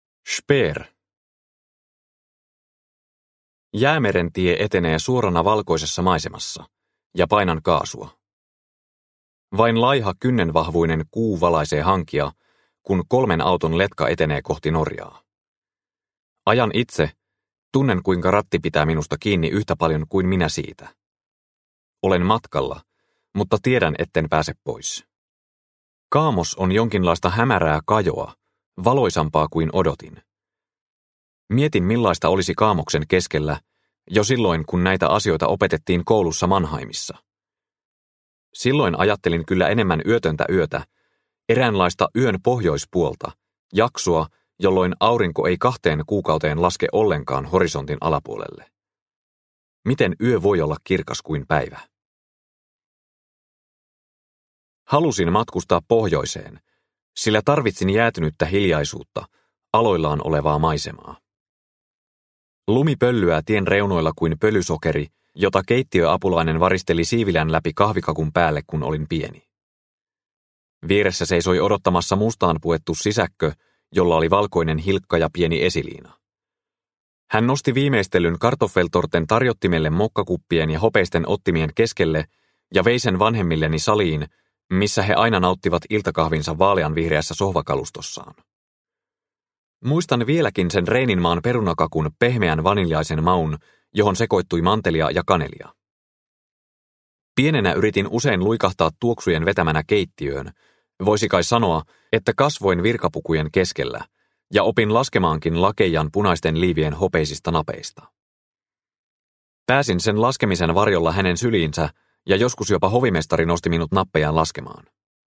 Dora, Dora – Ljudbok – Laddas ner